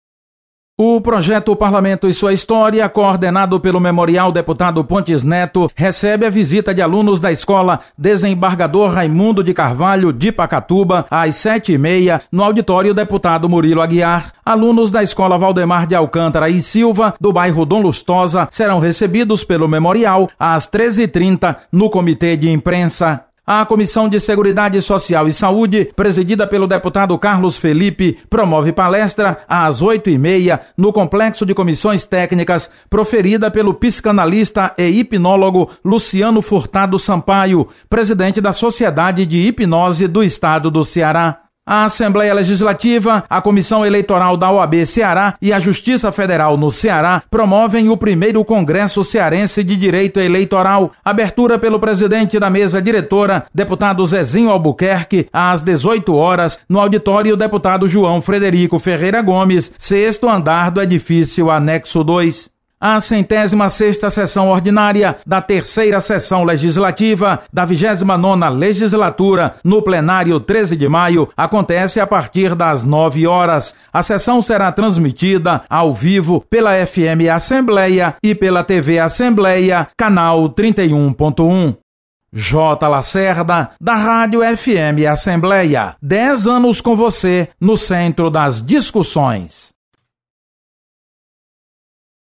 Acompanhe as atividades desta quinta-feira (31/08) na Assembleia Legislativa. Repórter